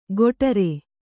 ♪ goṭare